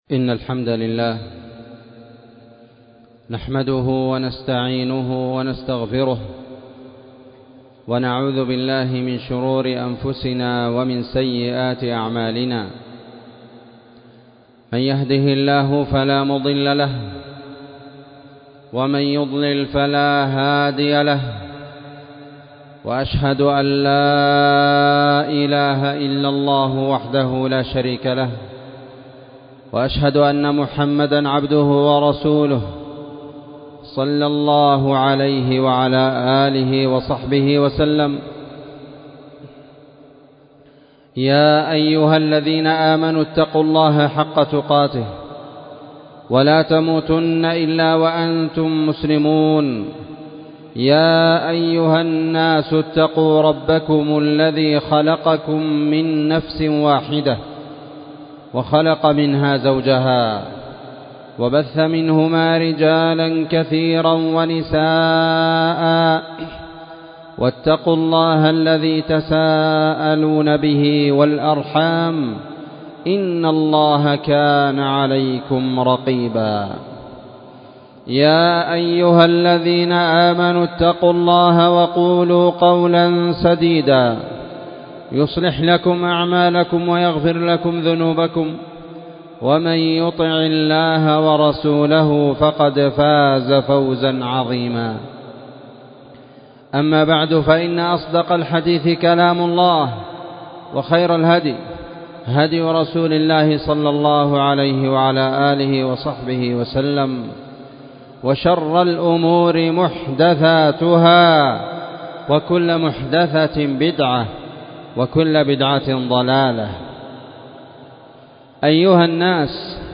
خطبة
والتي كانت في مسجد المجاهد- تعز